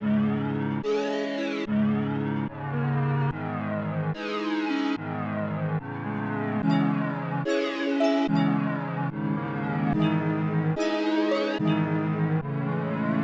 Tag: 145 bpm Trap Loops Synth Loops 2.23 MB wav Key : A